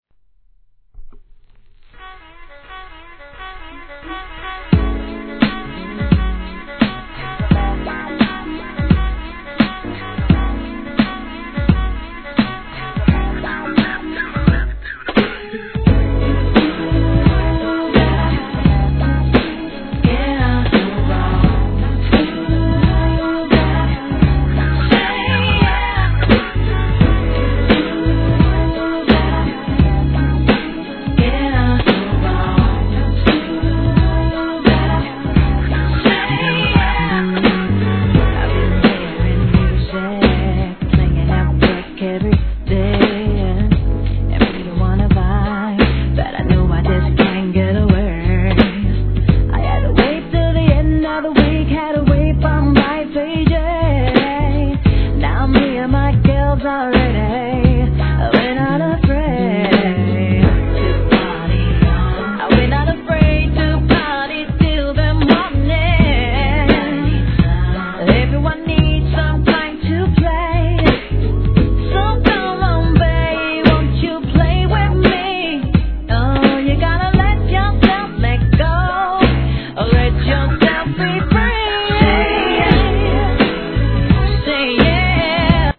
HIP HOP/R&B
この心地よさは一生物です☆